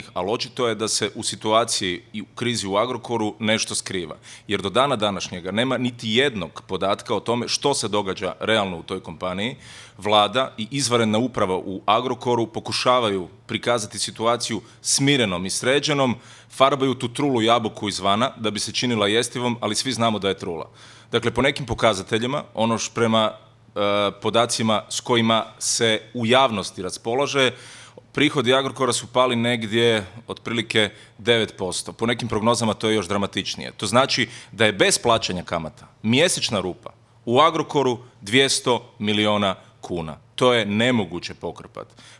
ZAGREB - "Svi su sve znali, zato smo i tražili Marićevu ostavku" rekao je predsjednik SDP-a Davor Bernardić u intervju tjedna Media servisa komentirajući posljednje medijske napise o stanju u Agrokoru.